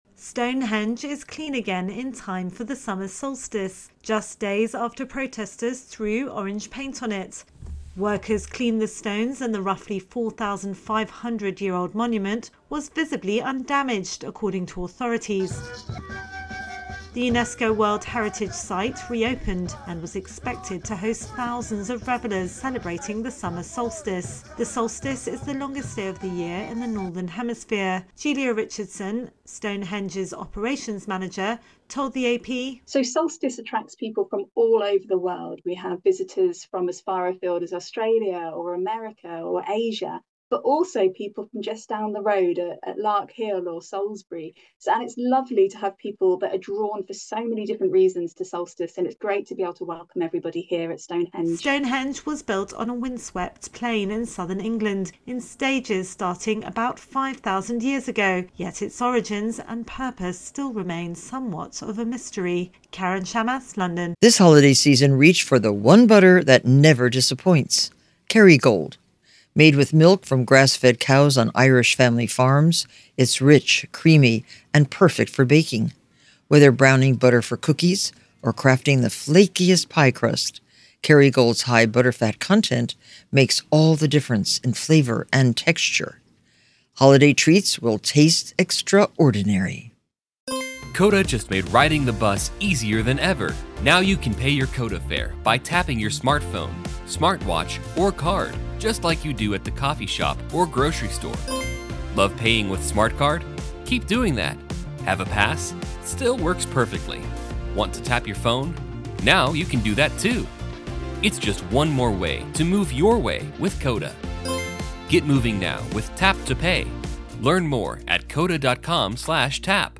reports on a protest on Stone henge ahead of solstice celebrations.